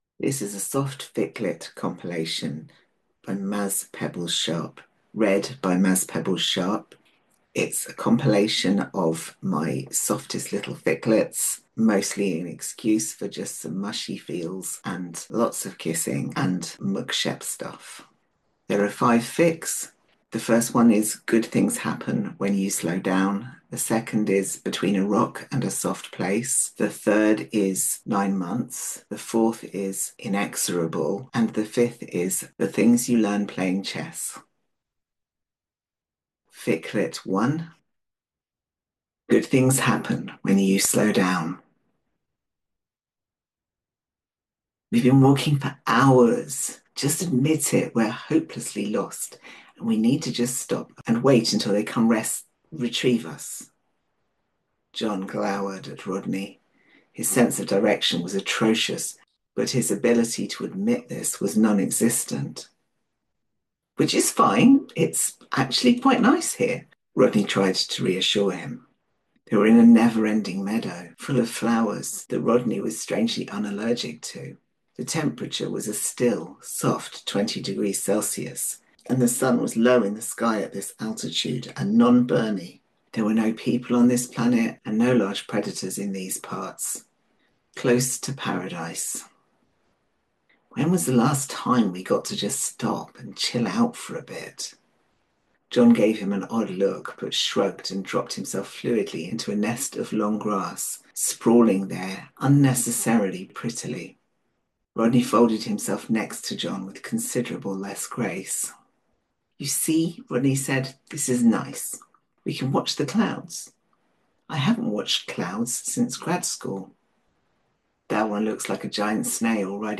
anthology|single reader | Audiofic Archive